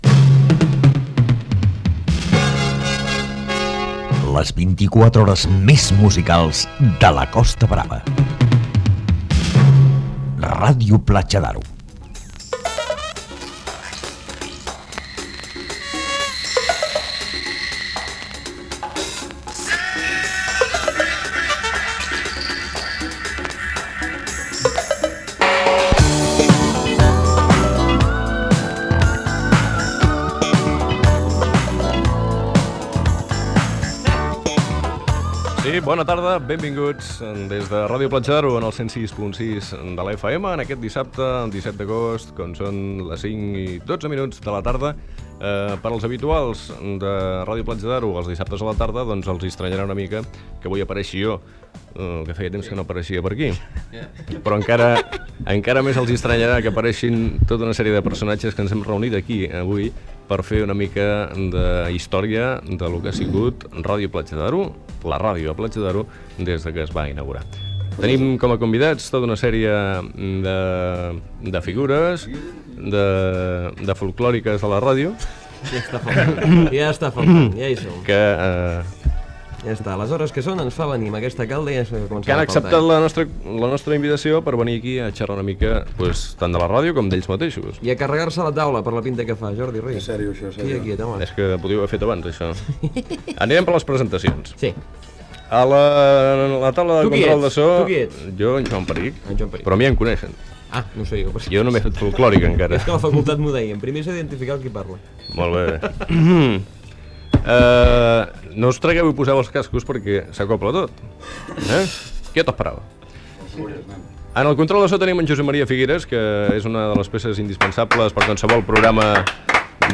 Entreteniment
FM
Fragment extret de l'arxiu sonor de Ràdio Platja d'Aro